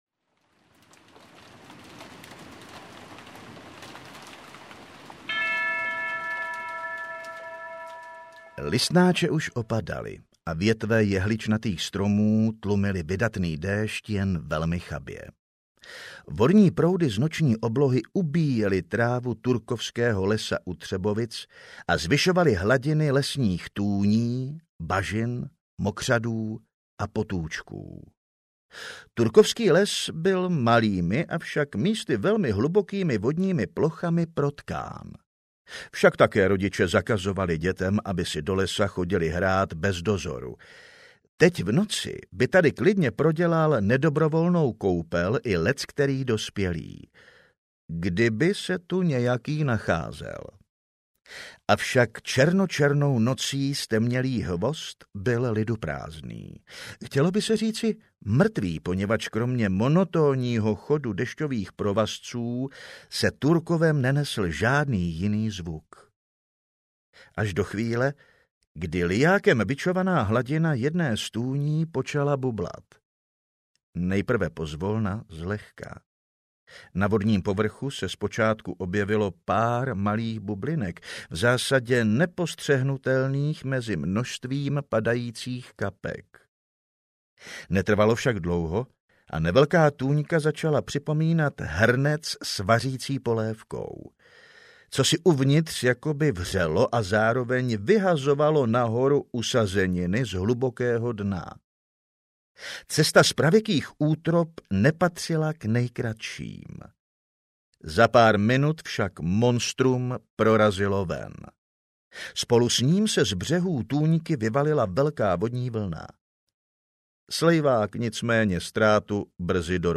Ostravský páter audiokniha
Ukázka z knihy